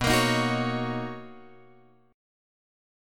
B Major 9th